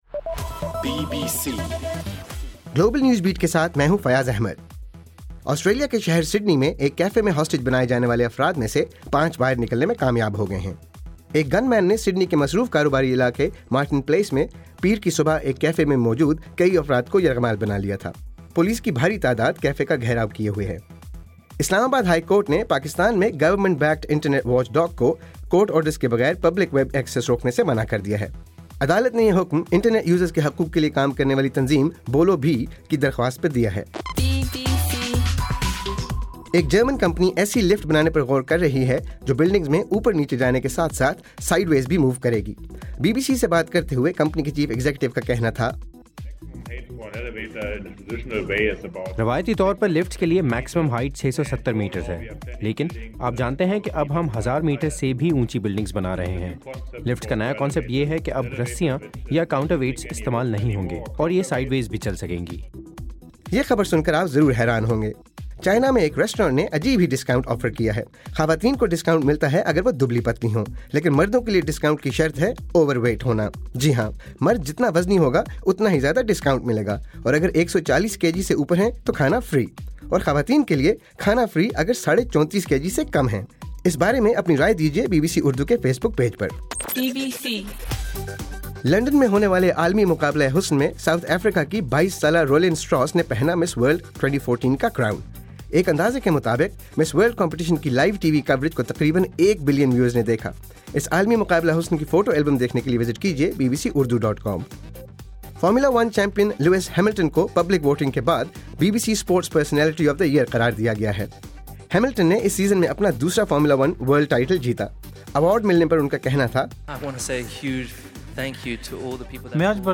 دسمبر 15: رات 8 بجے کا گلوبل نیوز بیٹ بُلیٹن